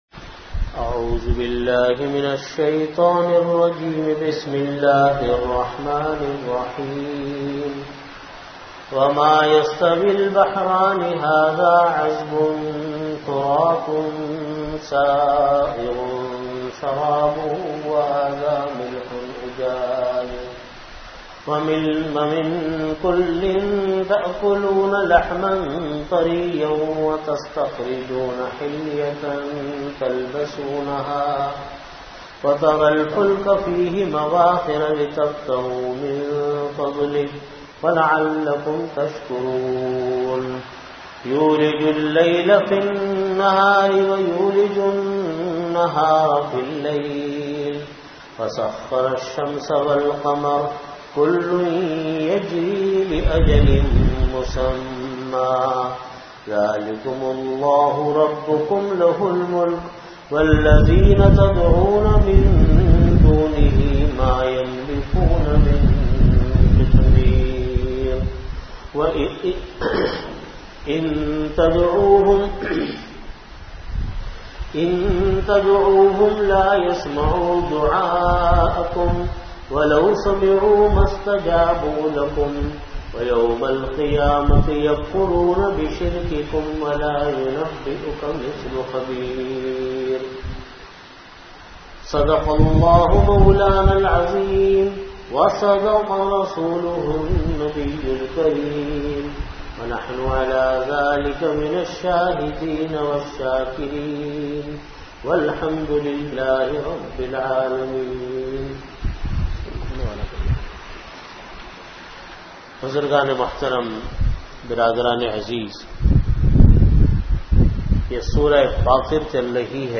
Audio Category: Tafseer
Time: After Asar Prayer Venue: Jamia Masjid Bait-ul-Mukkaram, Karachi